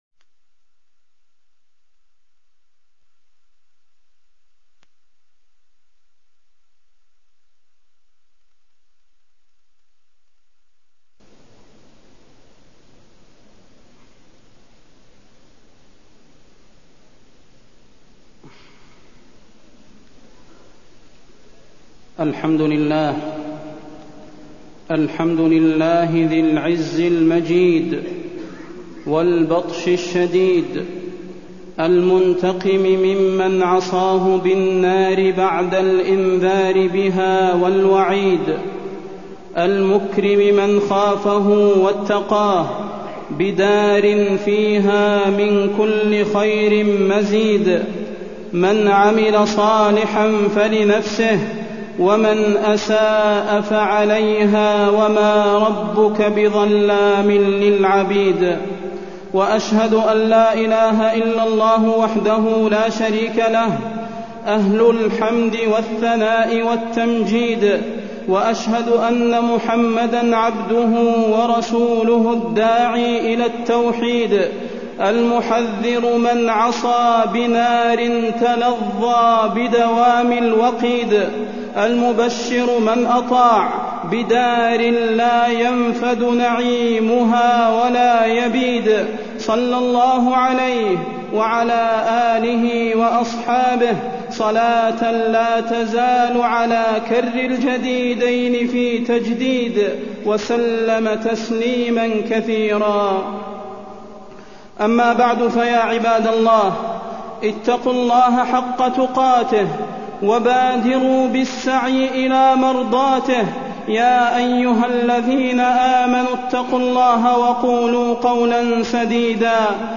فضيلة الشيخ د. صلاح بن محمد البدير
تاريخ النشر ٢٣ جمادى الأولى ١٤٢٣ هـ المكان: المسجد النبوي الشيخ: فضيلة الشيخ د. صلاح بن محمد البدير فضيلة الشيخ د. صلاح بن محمد البدير النار وأهوالها